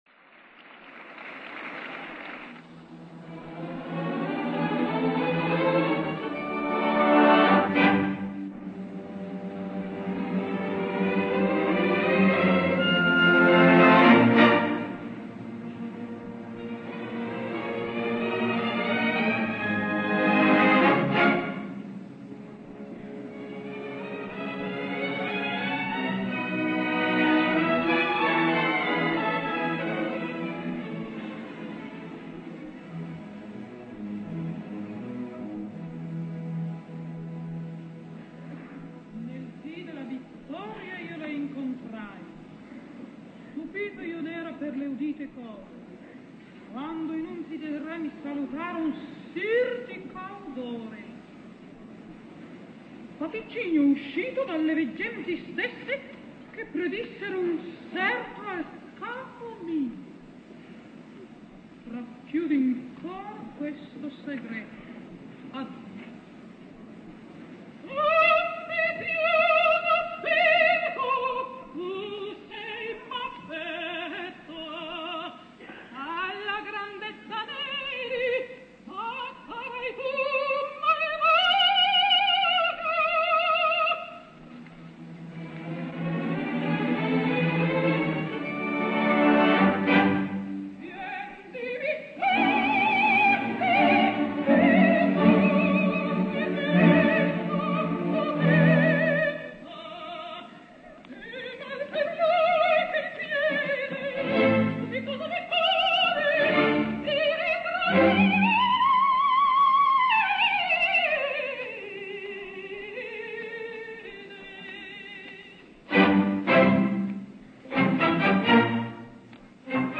Així cantava a la Scala l’any 1952 la seva entrada
Accetta il dono, Ascendivi a regnar” i la manera extraordinària com fa front a la cabaletta des de quasi un murmuri a un crescendo sanguinolent que encara guarda les coloratures hereves del belcantisme però sense cap semblança a les heroïnes belcantistes que ella també afrontat amb extraordinari encert, com Anna Bolena, Lucia o Norma.